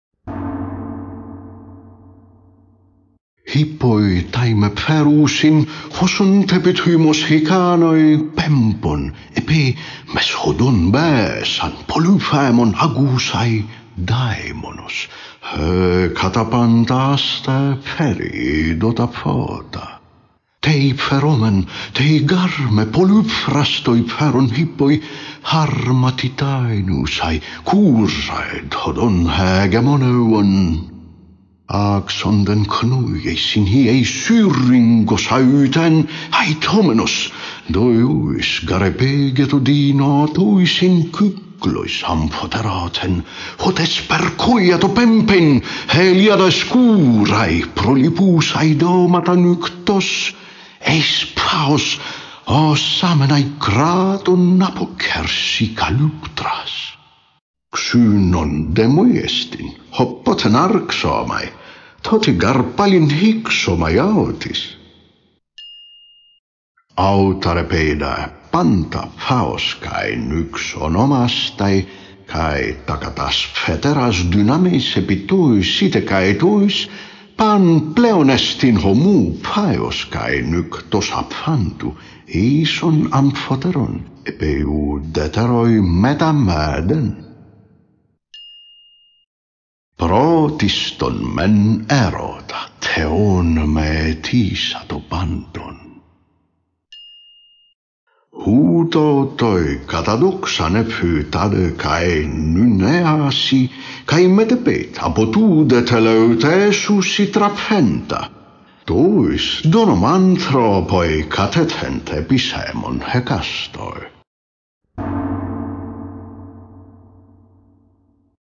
Unabridged
The only work of Parmenides that has survived to our days are fragments of his poem, all of which are recited in this audiobook in the original ancient Greek text (reconstructed pronunciation).